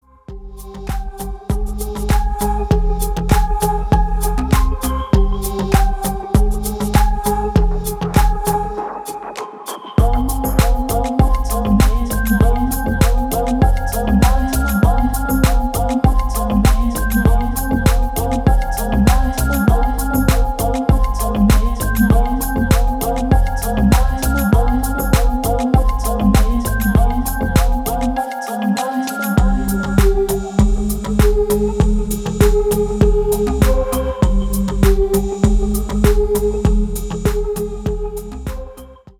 • Качество: 320, Stereo
deep house
атмосферные
Electronic
спокойные
красивый женский голос